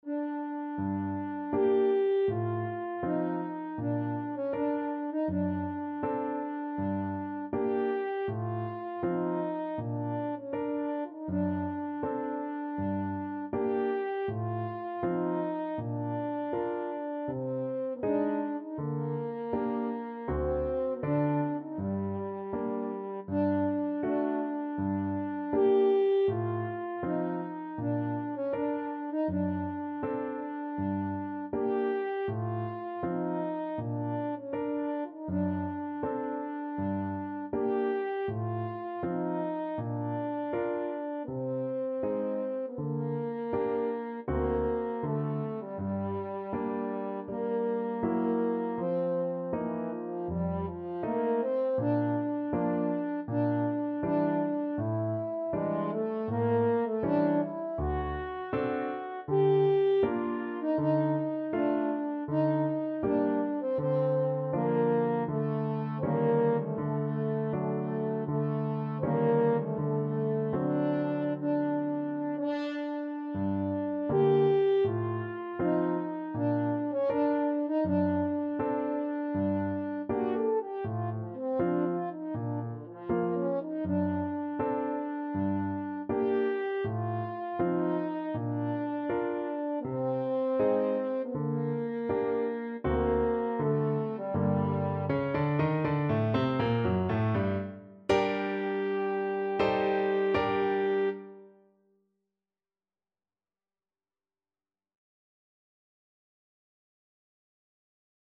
4/4 (View more 4/4 Music)
Andante = c.80
G minor (Sounding Pitch) D minor (French Horn in F) (View more G minor Music for French Horn )
French Horn  (View more Intermediate French Horn Music)
Classical (View more Classical French Horn Music)